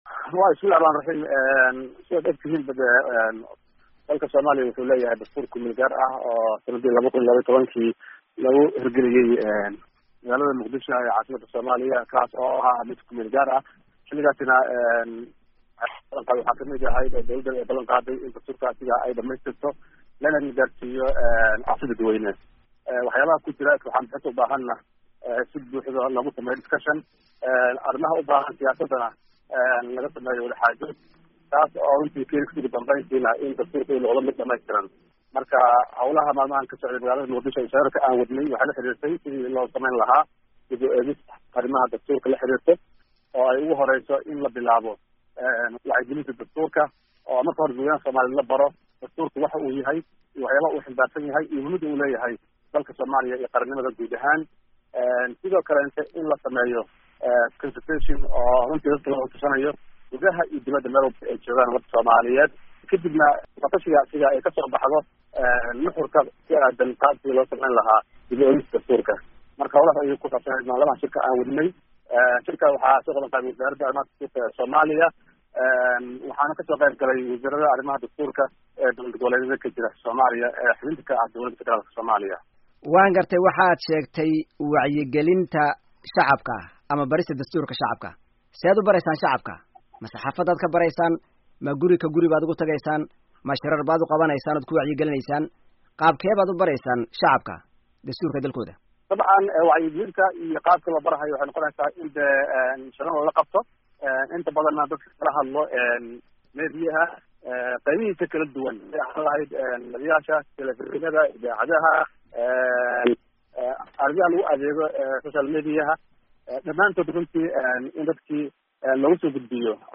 Wareysi: Wasiiru Dowlaha Dastuurka